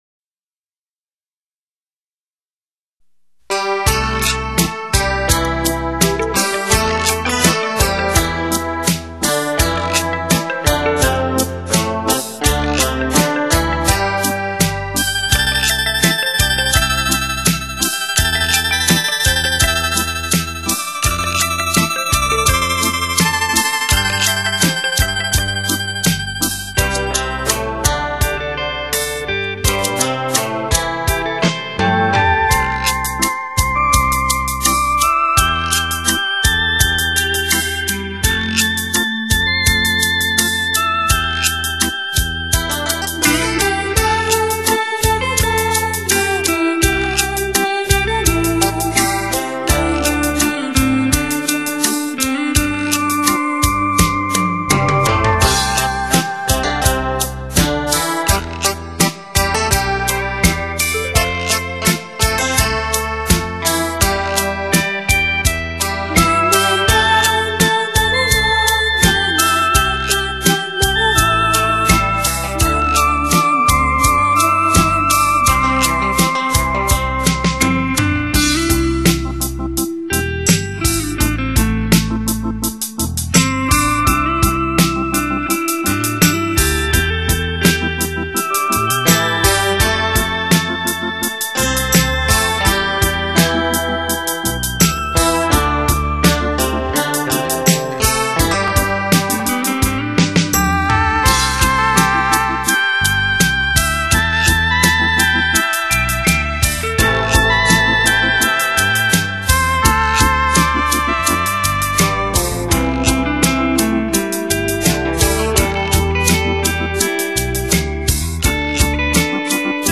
亚洲纯音
不错的电子琴音乐，谢谢分享，收下了。